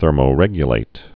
(thûrmō-rĕgyə-lāt)